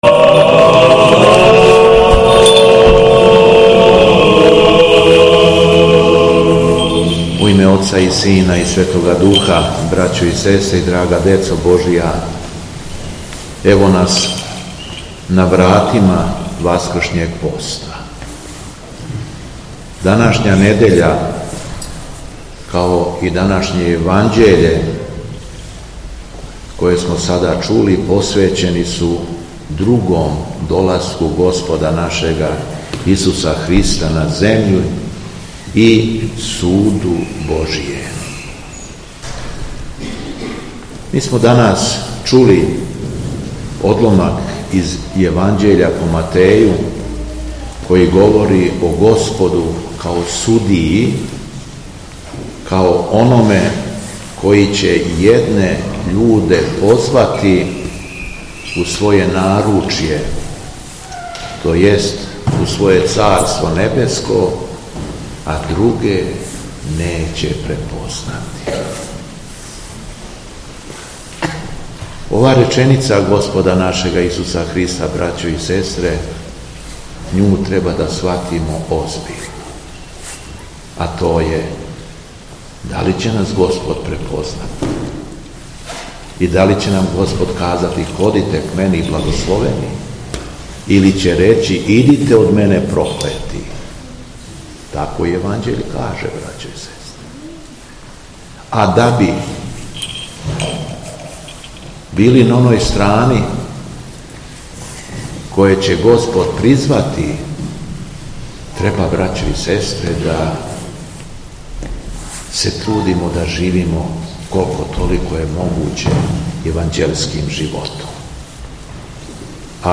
СВЕТА АРХИЈЕРЕЈСКА ЛИТУРГИЈА У ЦРКВИ СВЕТИХ АПОСТОЛА ПЕТРА И ПАВЛА У РАЧИ У НЕДЕЉУ МЕСОПУСНУ 2025. ГОДИНЕ - Епархија Шумадијска
Беседа Његовог Високопреосвештенства Митрополита шумадијског г. Јована
По прочитаном Јеванђељу митрополит је произнео своју омилију и рекао: